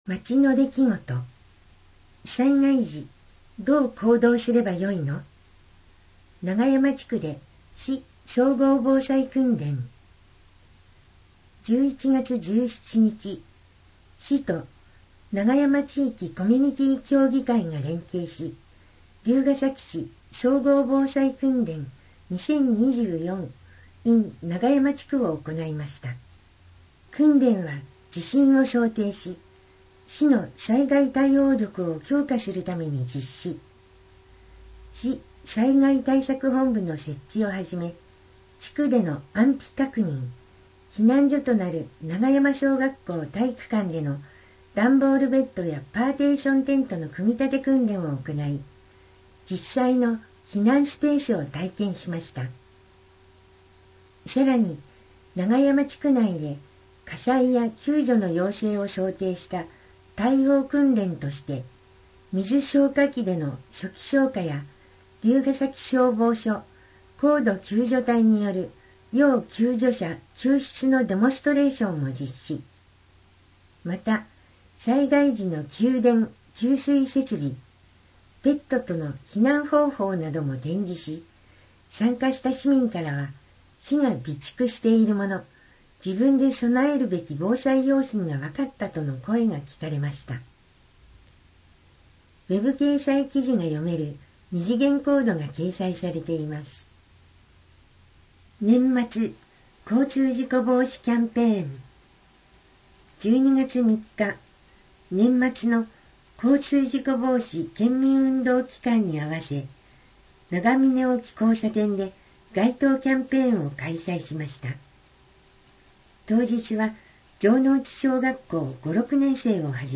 音訳データダウンロード
※MP3データは「龍ケ崎朗読の会」のご協力により作成しています。